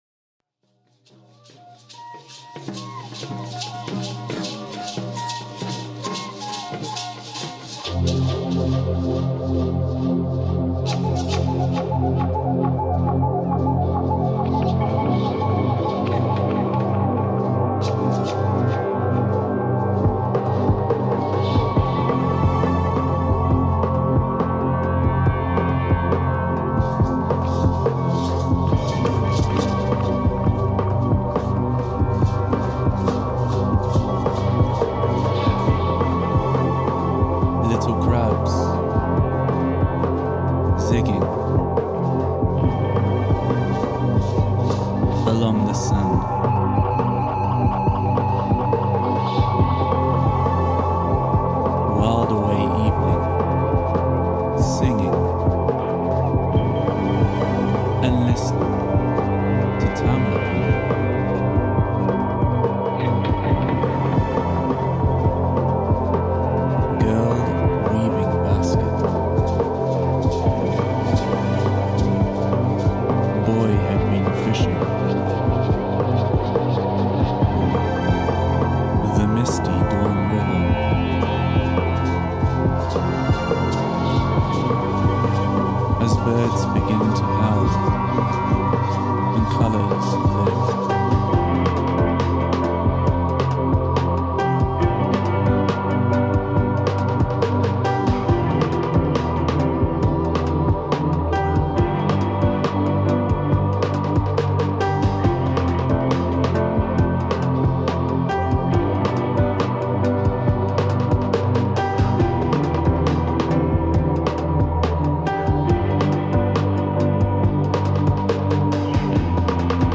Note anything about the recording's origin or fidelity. The recording that I selected, made on 5-inch reel tape, captures the sounds of the local Emberá people playing music with flute, drums and other percussive instruments.